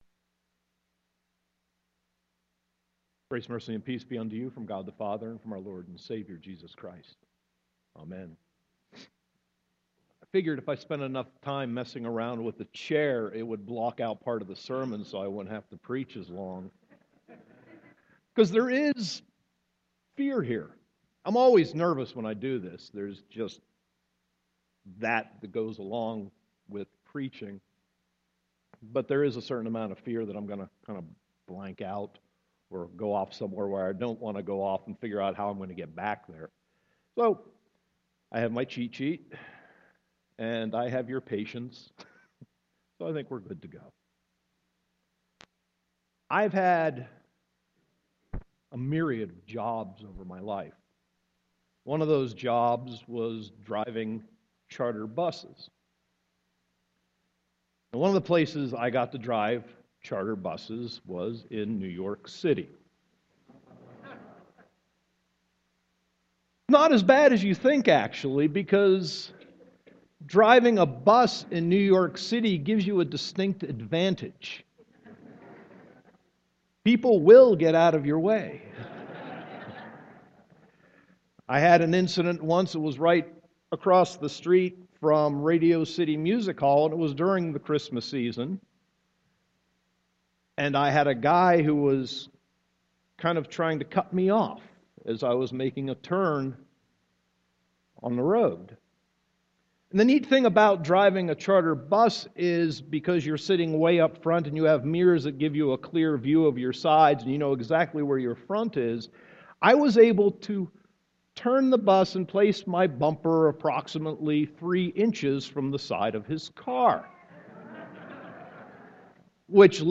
Sermon 12.6.2015